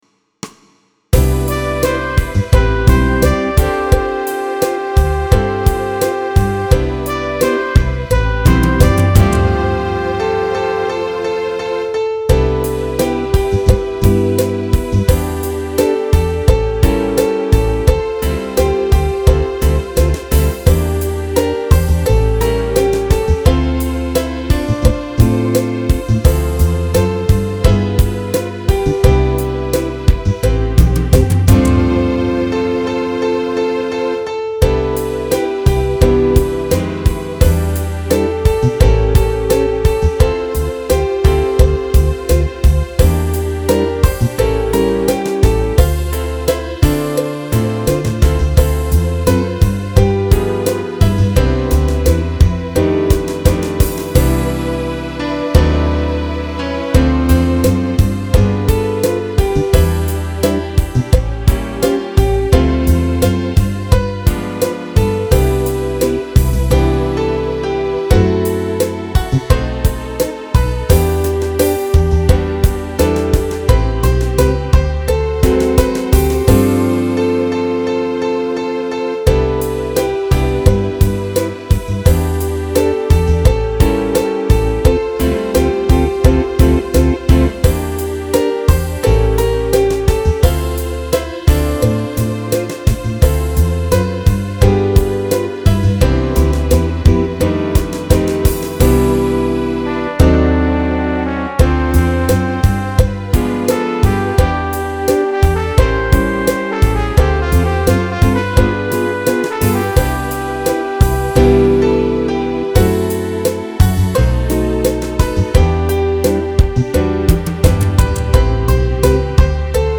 It’s on the jazz/swing list.